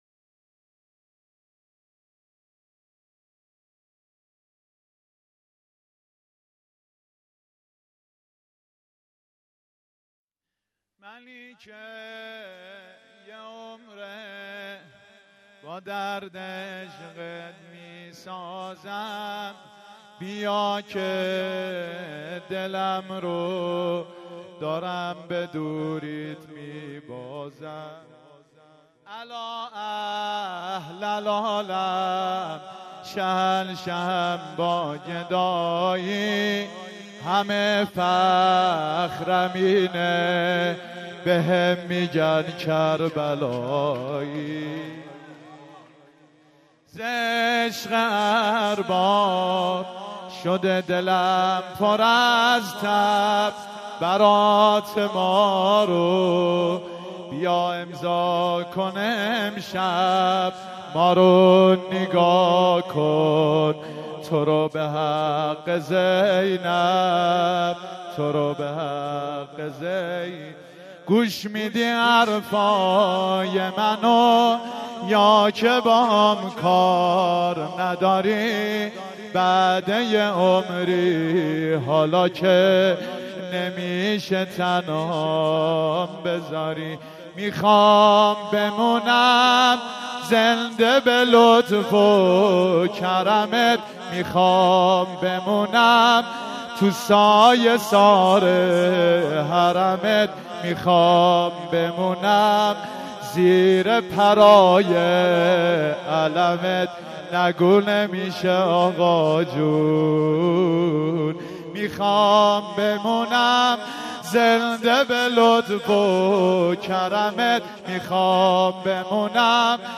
مداح
مناسبت : شب ششم رمضان
قالب : زمینه